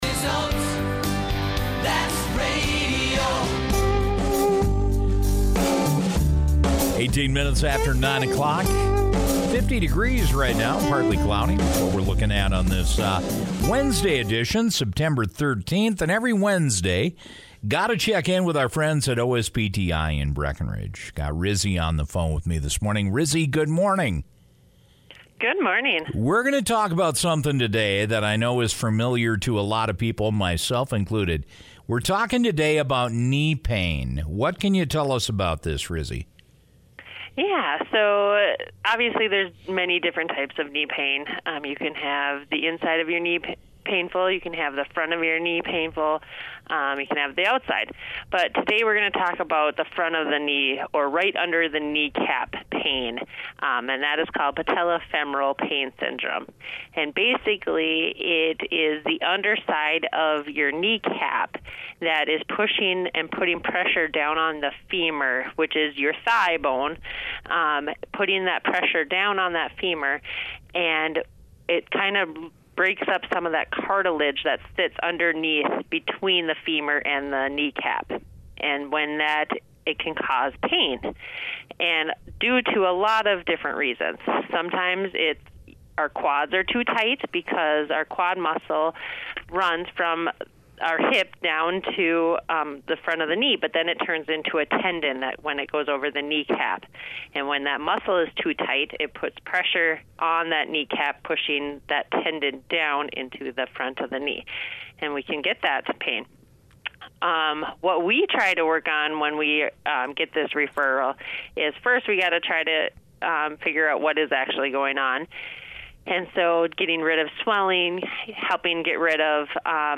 Wednesday morning radio segment